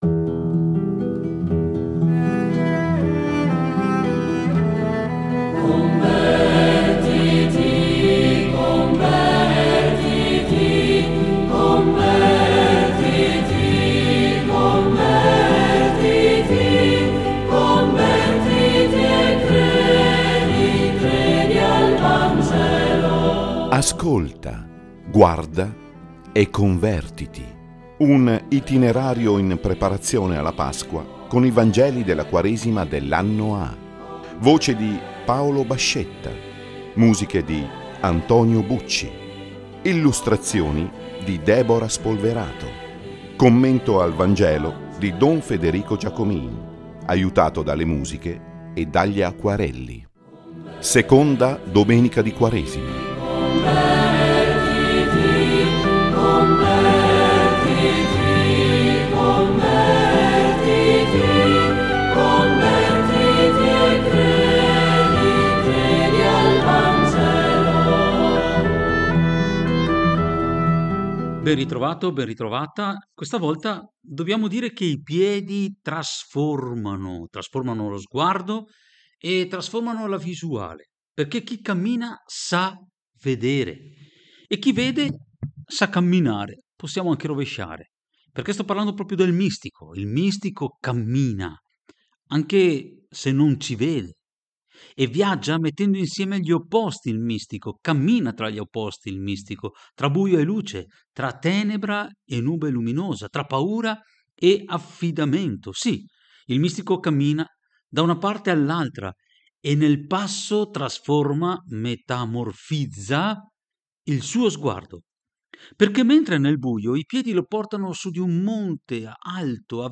Meditazione